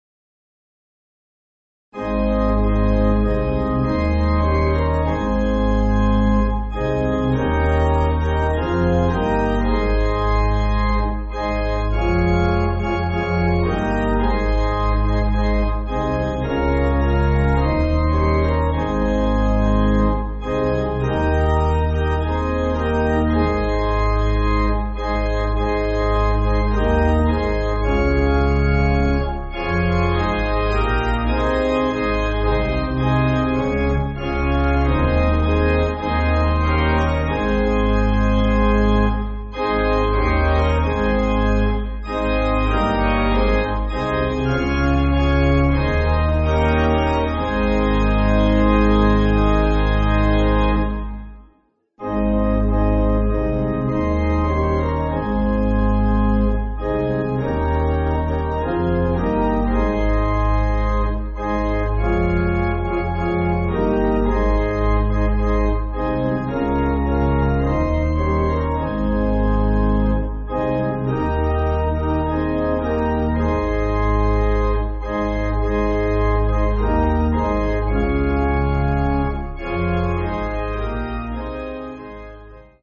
(CM)   3/Ab